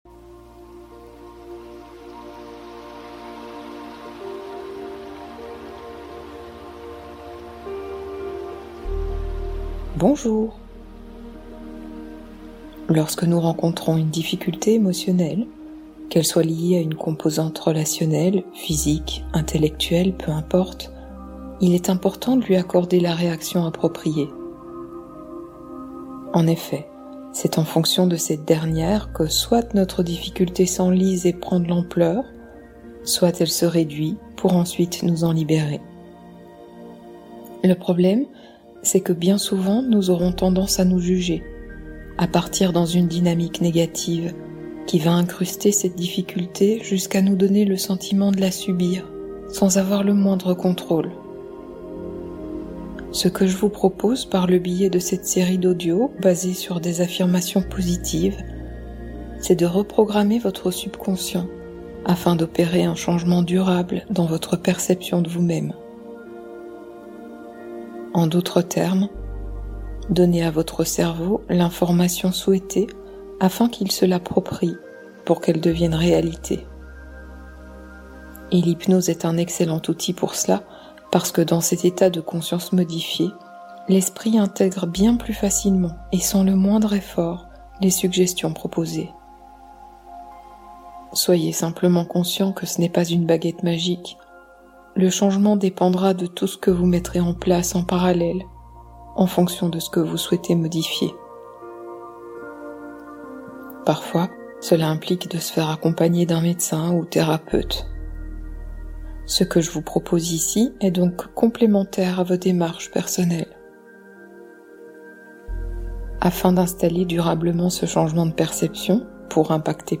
Devenez non-fumeur pendant votre sommeil (hypnose de reprogrammation totale)